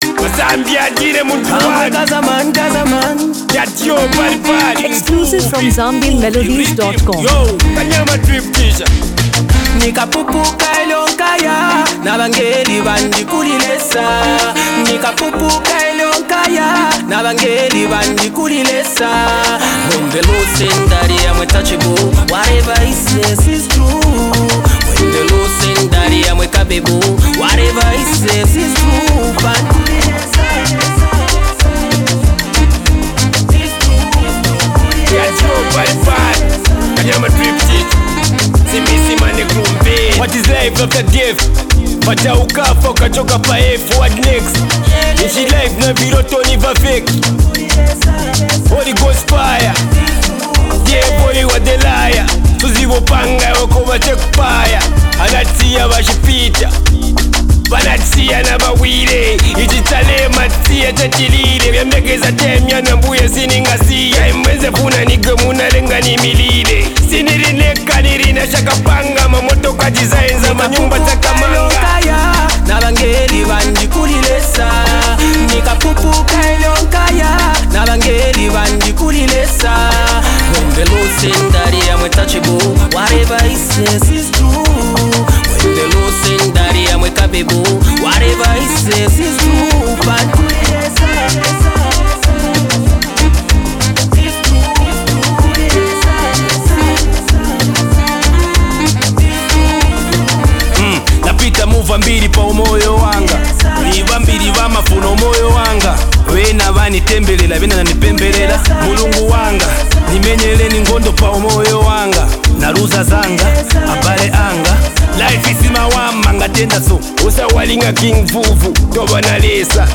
Latest Zambian Afro-Pop Song
is a vibrant Afro-pop anthem
Loved locally, it blends catchy melodies.
Genre: Afro-beats/Afro-Fusion